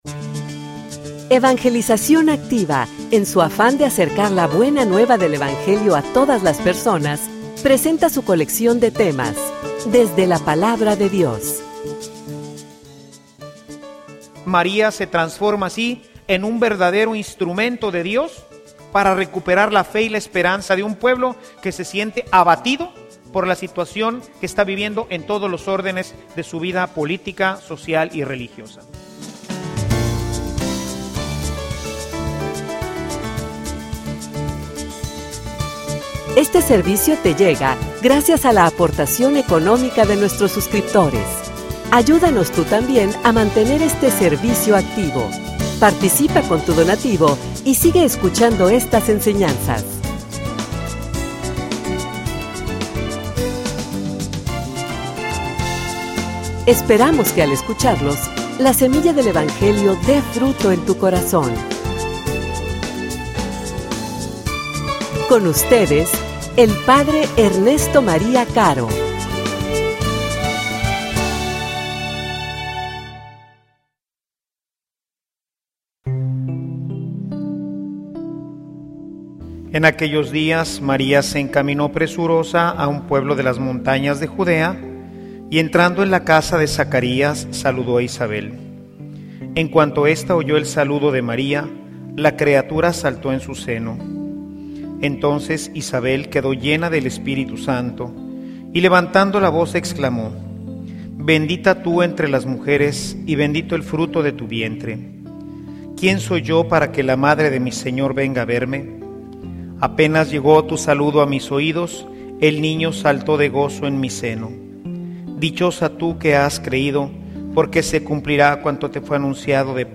homilia_Promotores_de_esperanza.mp3